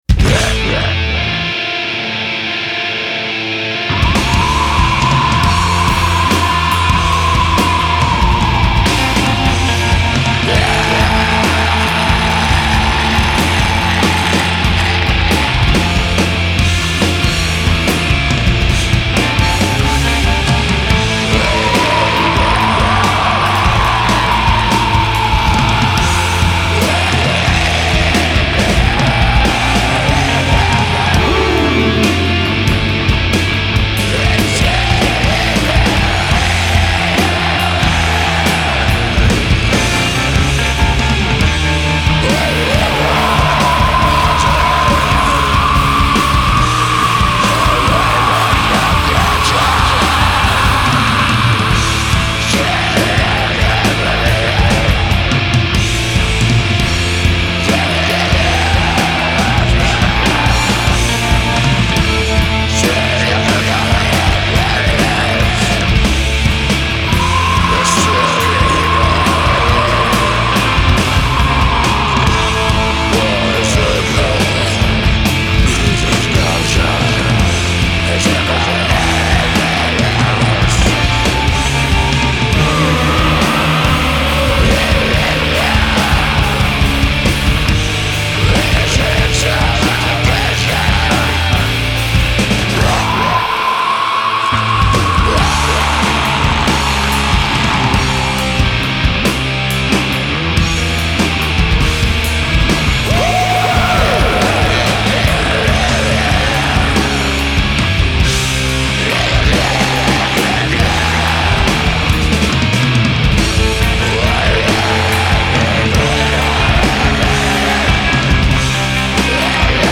ve skutečném studiu